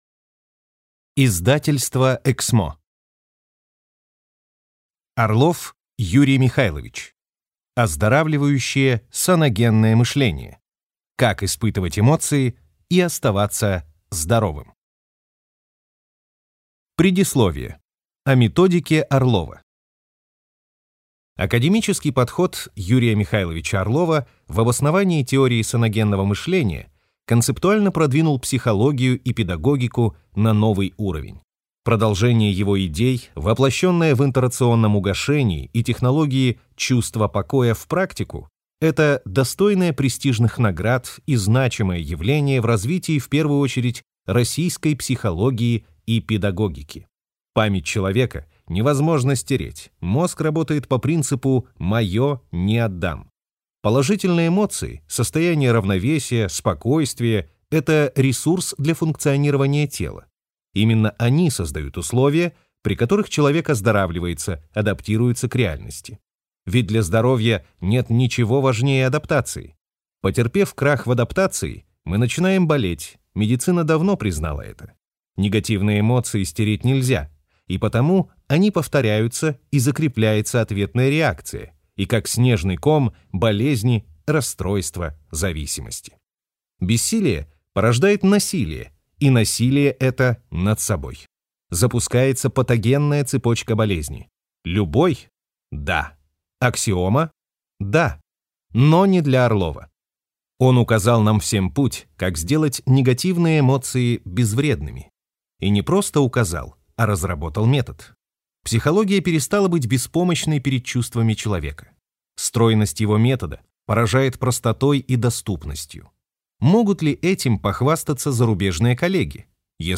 Аудиокнига Оздоравливающее саногенное мышление. Как испытывать эмоции и оставаться здоровым.